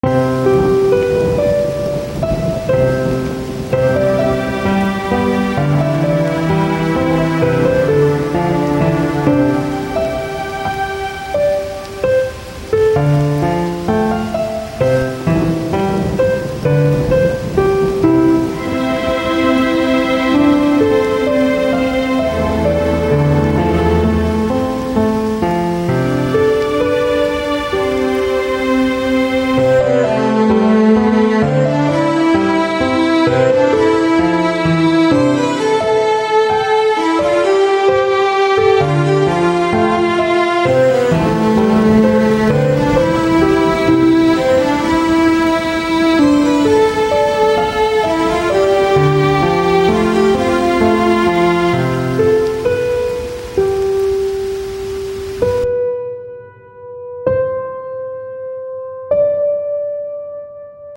Миди-звук, правда, портит картину.